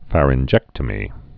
(fărĭn-jĕktə-mē)